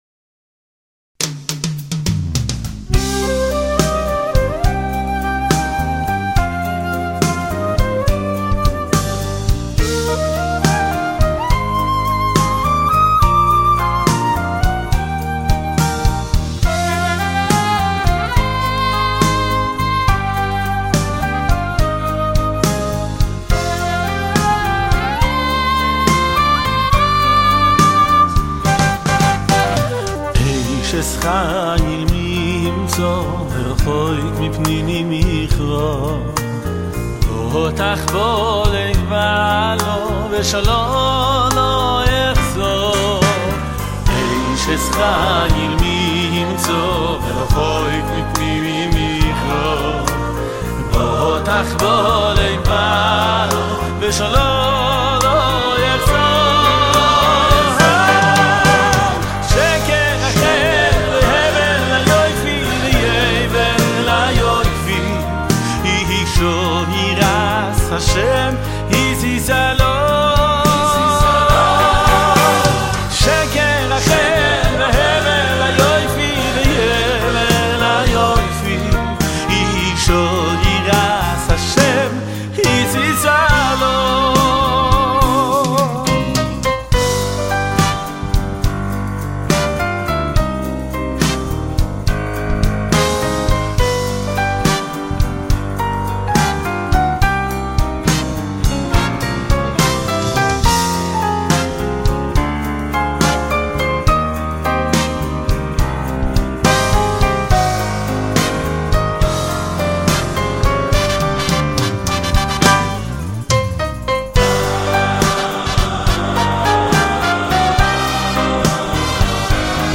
בלשון הקודש בהגייה אשכנזית חסידית